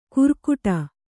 ♪ kurkuṭa